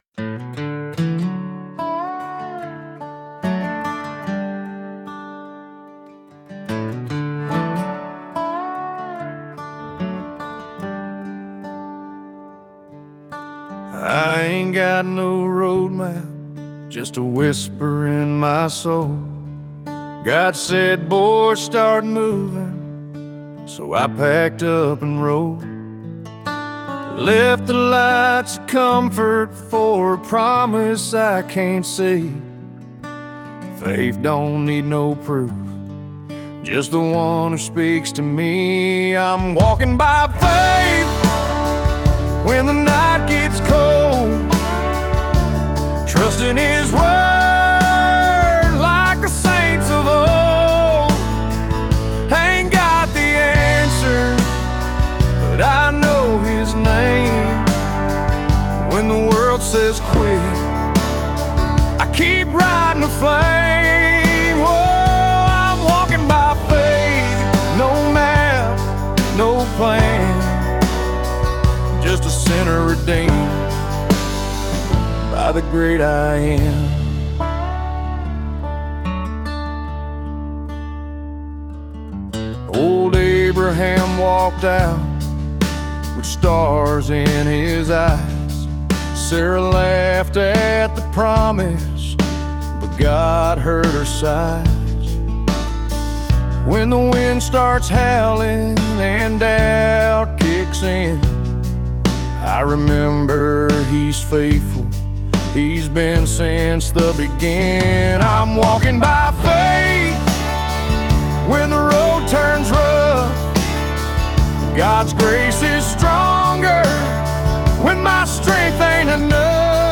gritty outlaw-country track